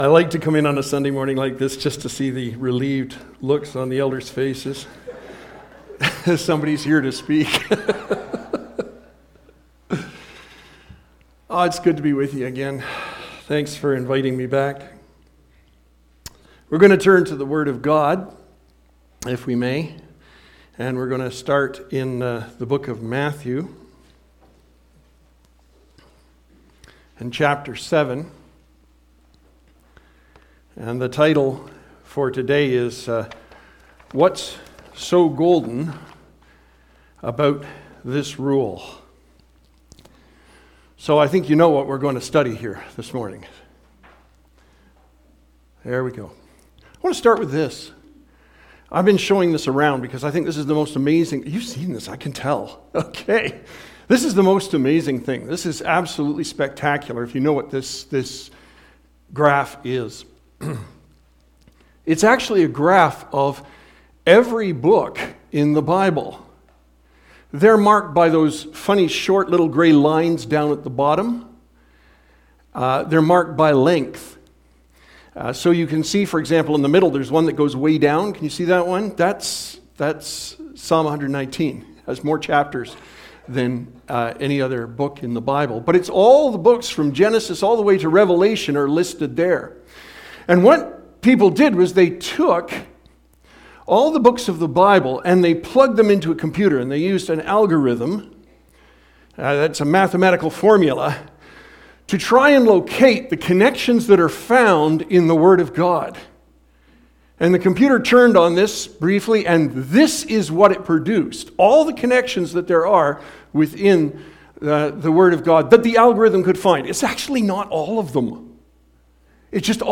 Sermon Audio and Video What's so Golden About this Rule?